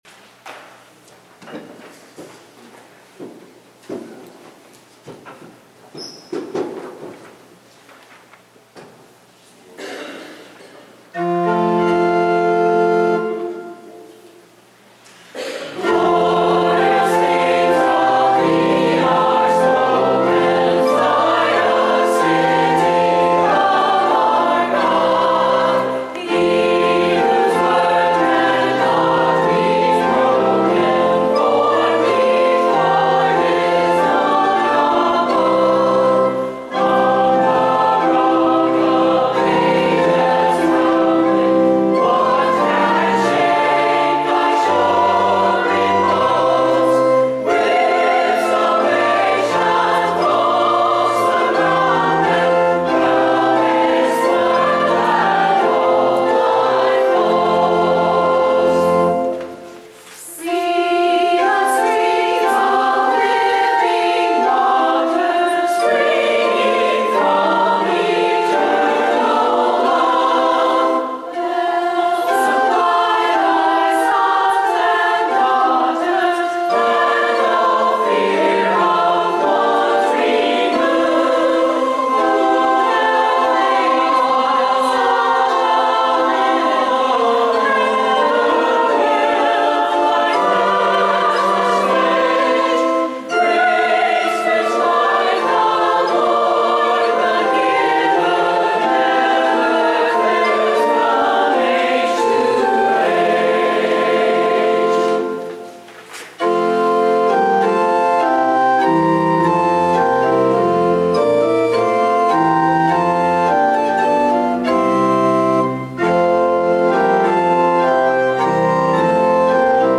At the end I include the track from the choral anthem, “Glorious Things of Thee Are Spoken.”
Anthem: Glorious Things of Thee are Spoken